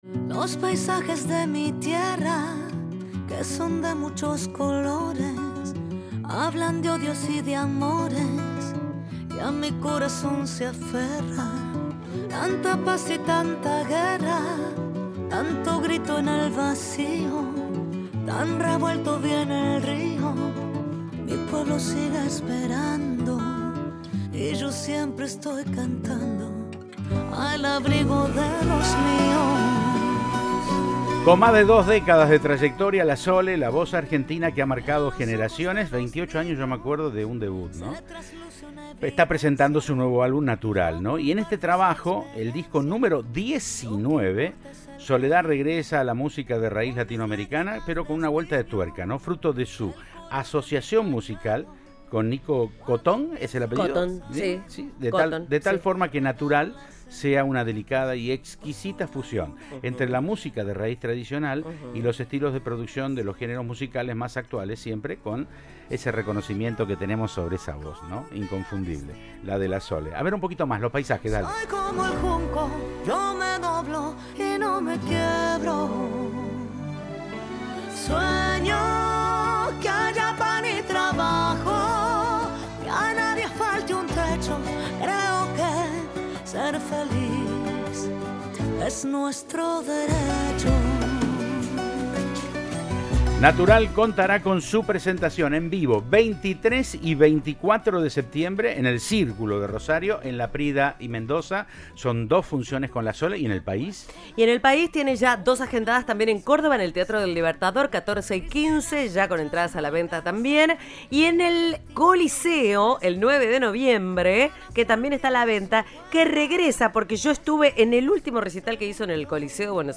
El Chaqueño Palavecino en el Festival de Folclore en el Agua - 100 Noches Festivaleras 2026 - Espectáculos